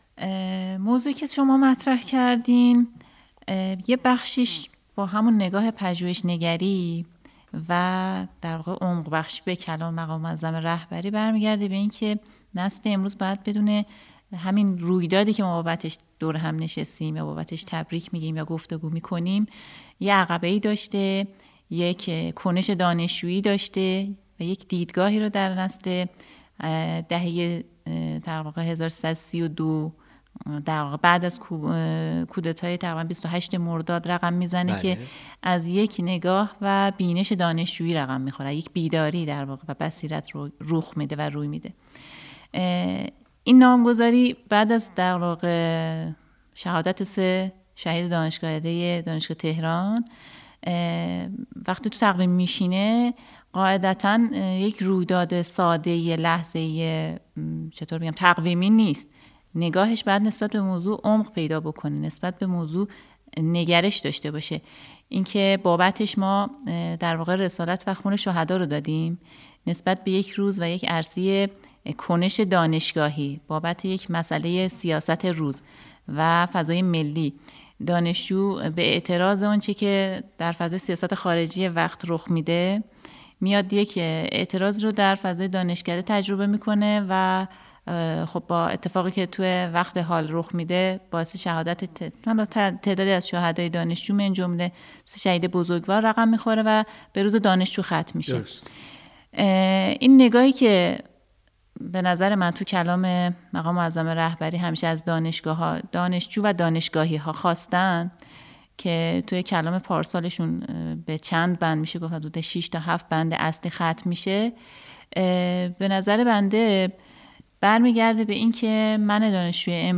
گفت‌وگویی صریح